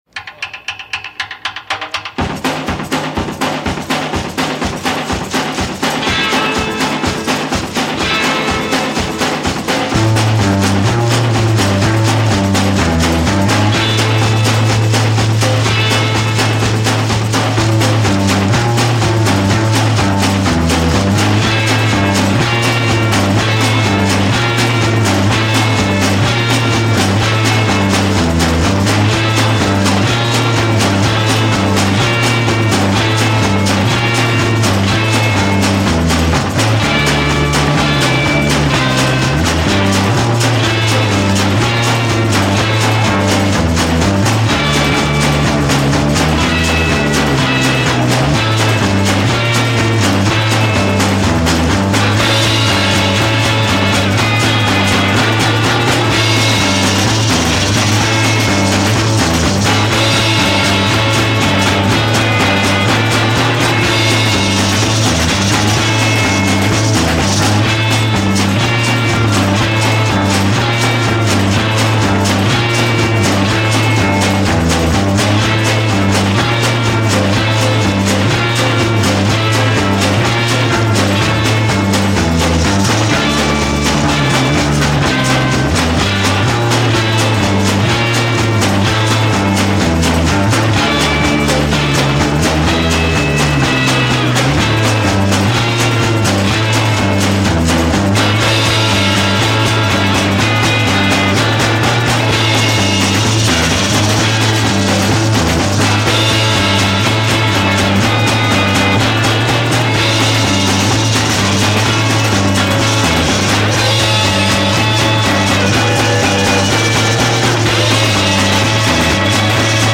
גראז', Pאנק וצ'ה צ'ה = רוקנ'רול
מלא המנוני התפרעויות לצד קטעים אינסטרומנטליים מלוכלכים.